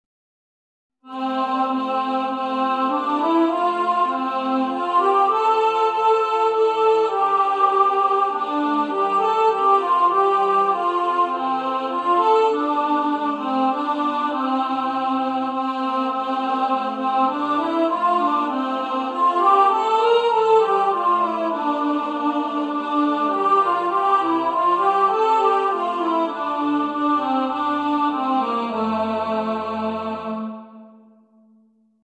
Away In A Manger (Alto) | Ipswich Hospital Community Choir